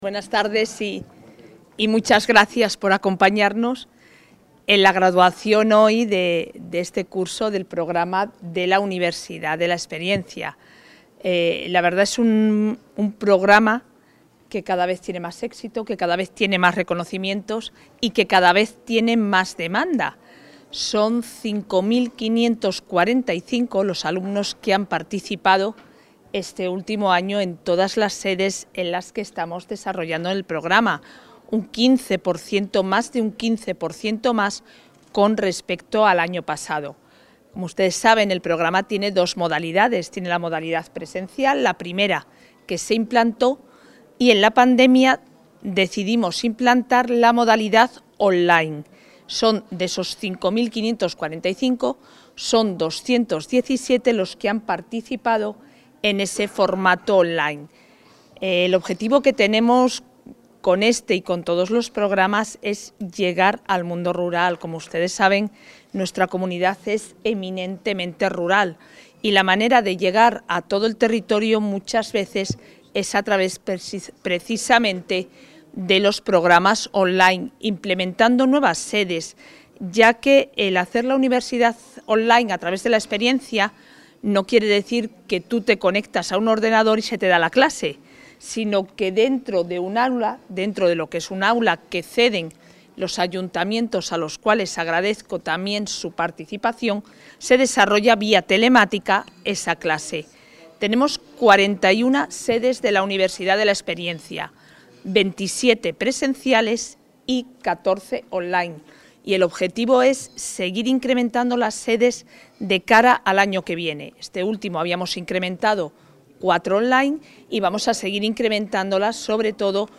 Intervención de la consejera.
La consejera de Familia e Igualdad de Oportunidades preside el acto de graduación en la Universidad de Valladolid y confirma que continuará la ampliación de las sedes el próximo curso, especialmente de la modalidad online, para atender la alta demanda.